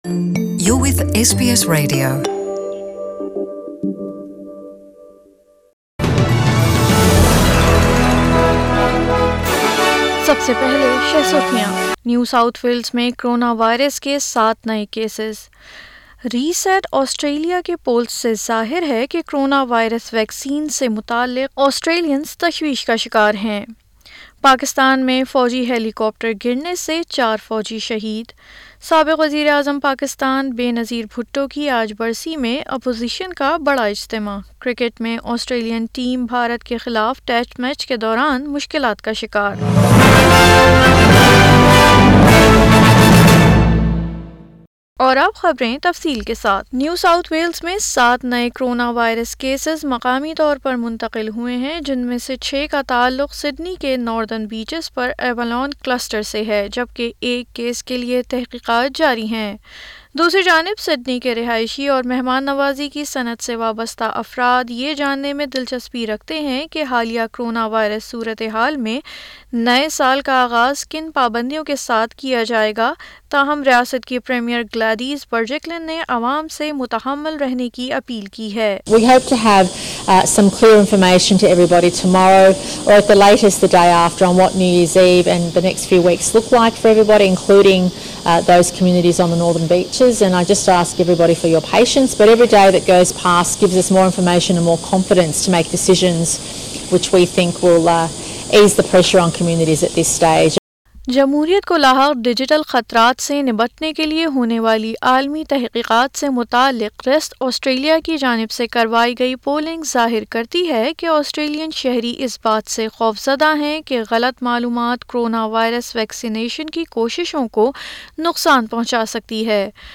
اردو خبریں 27 دسمبر 2020